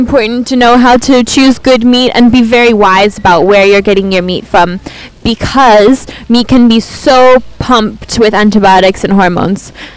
Newbie needs help with fixing saturated audio
Newest obstacle: I let someone I interviewed hold the microphone themselves and they held it way to close to their mouth, the audio is saturated and I don’t know what to do!
I’ve uploaded 10 seconds of the file- it’s in stereo, not mono.